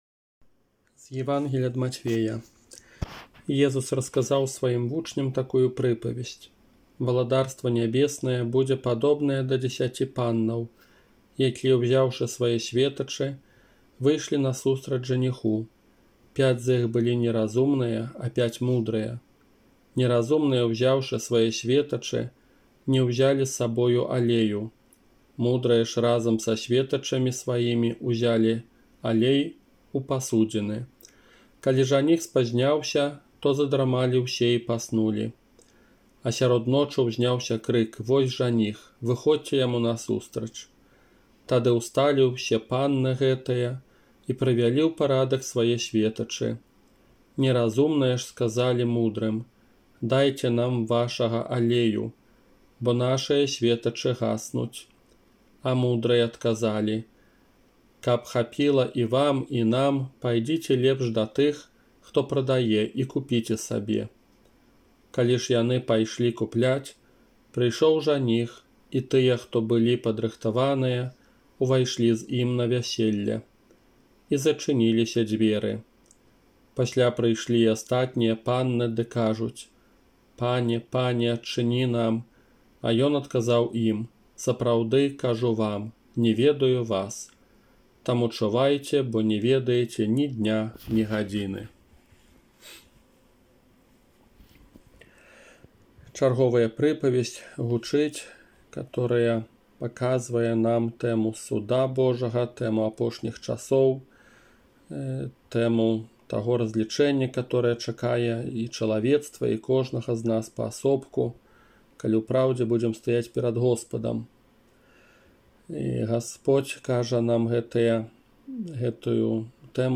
Казанне на трыццаць другую звычайную нядзелю 8 лістапада 2020 года